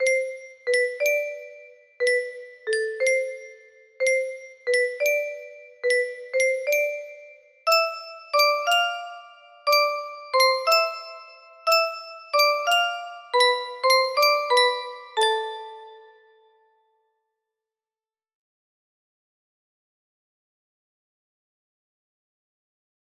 Full range 60
Just the Violin Melody.